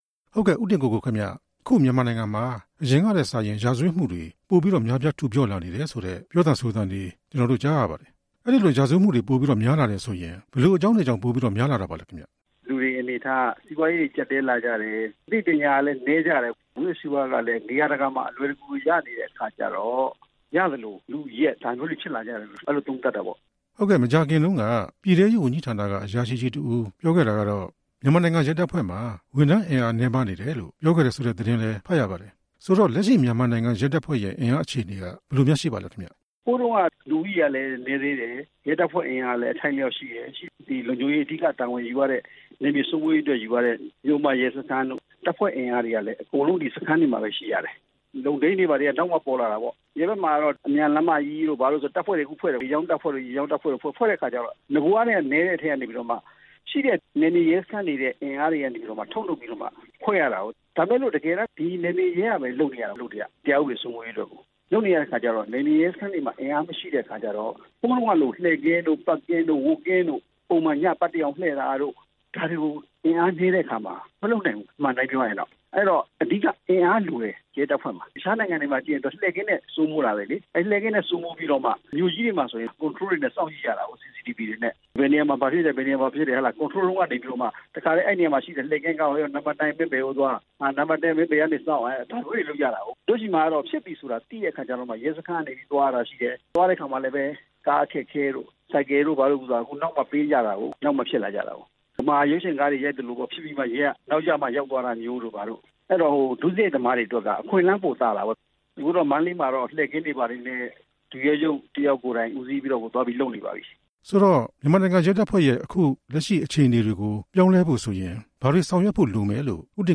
ဆက်သွယ်မေးမြန်းခဲ့ပါတယ်။